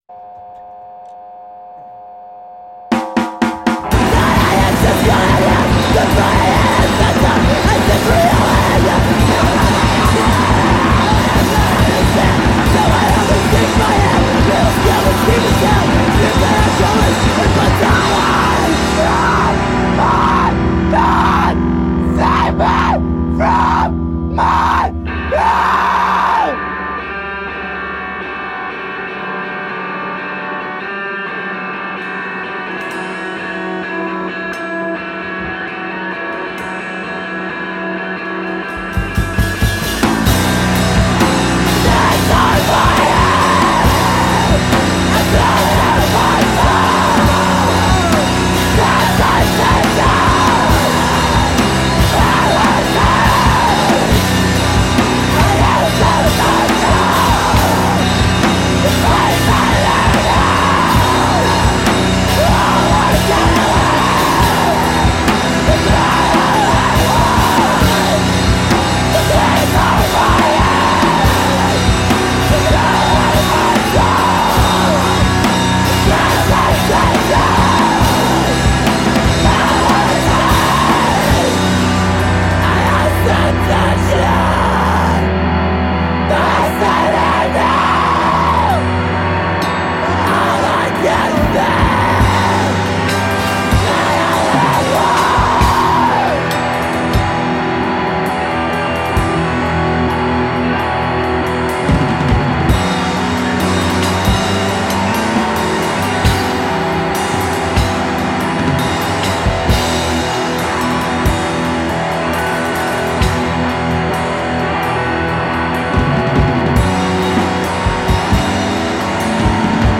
This is the kind of emo shit that I like